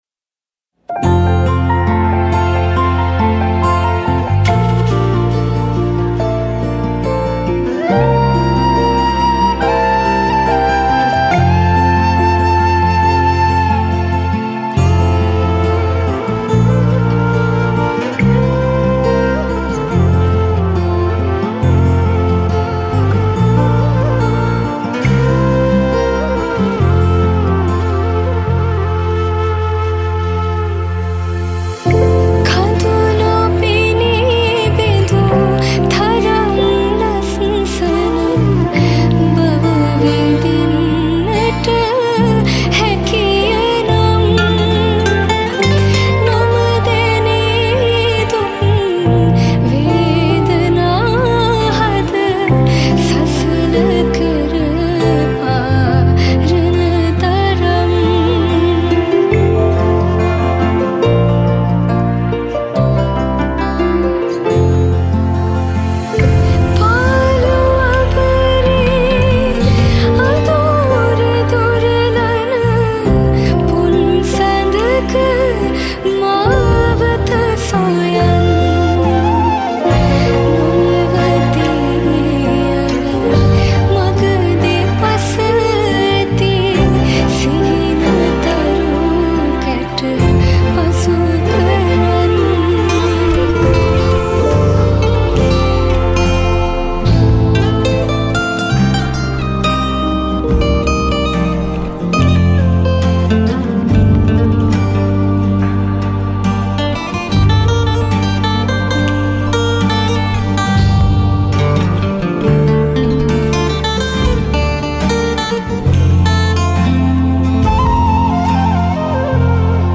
FLUTE
LEAD GUITAR
RHYTHEM GUITAR
BASS
PERCUSSION
KEYS